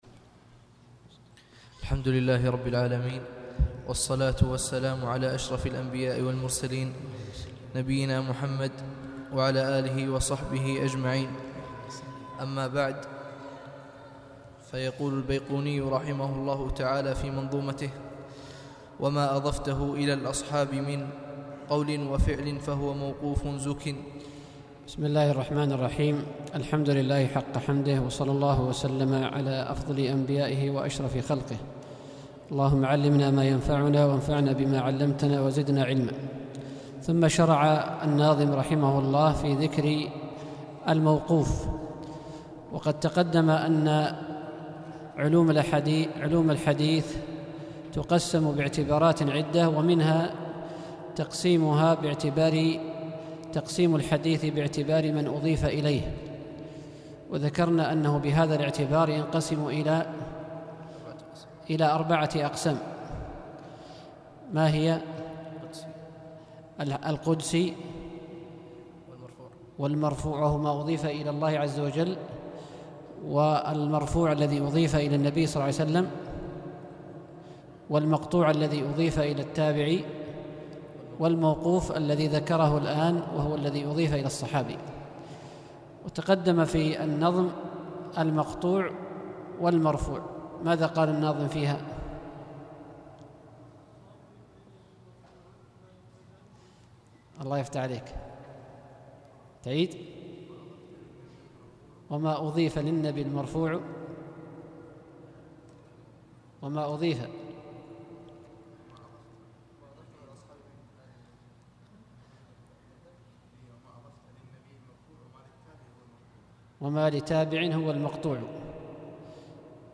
الدرس السابع: الموقوف، المرسل، الغريب، المنقطع